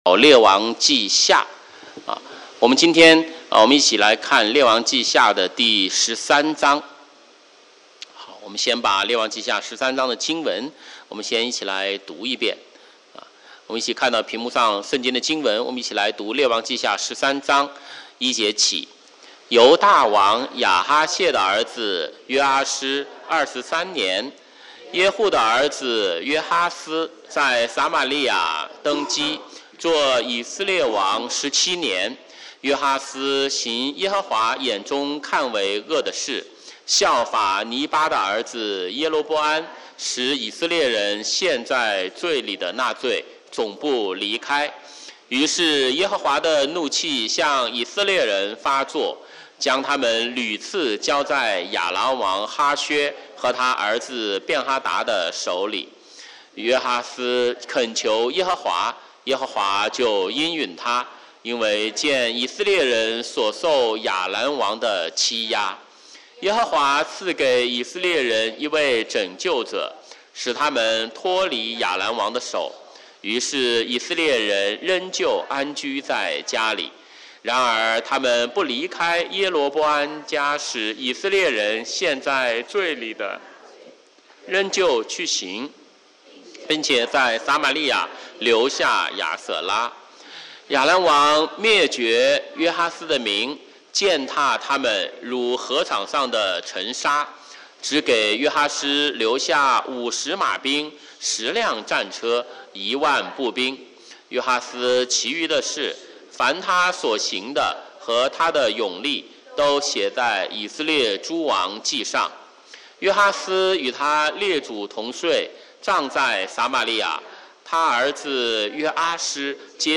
週五晚上查經講道錄音